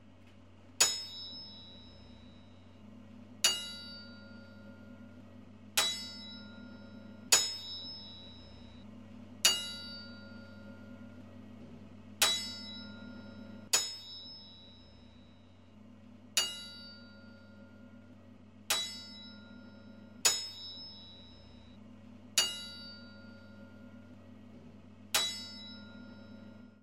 金属瓶
描述：金属水瓶的声音在有水和无水的情况下被敲击和摇晃。
标签： 攻丝 滴落 金属
声道立体声